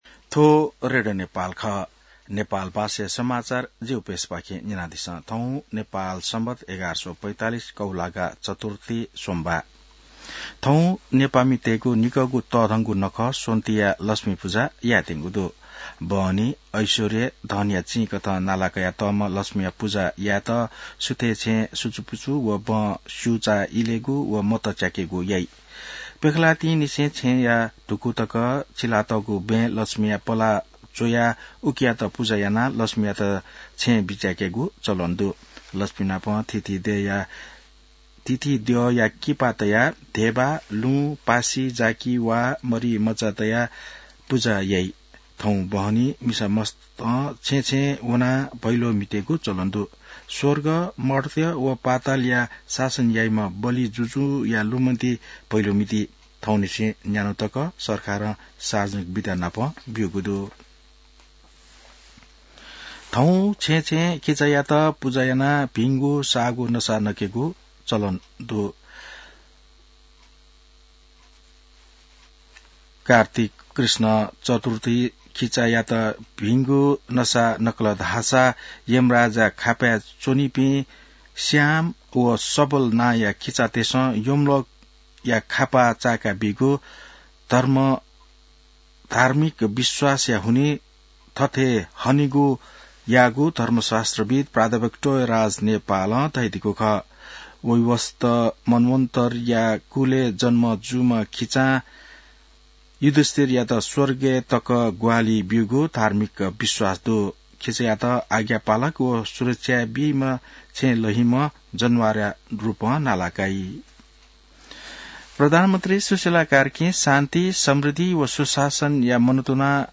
नेपाल भाषामा समाचार : ३ कार्तिक , २०८२